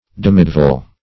Demidevil \Dem"i*dev`il\, n.